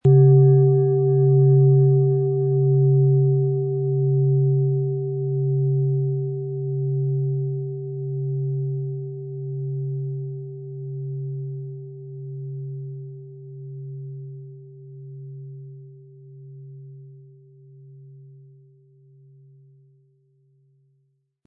OM Ton
• Tiefster Ton: Jupiter
Im Audio-Player - Jetzt reinhören hören Sie genau den Original-Ton der angebotenen Schale.
Spielen Sie die Schale mit dem kostenfrei beigelegten Klöppel sanft an und sie wird wohltuend erklingen.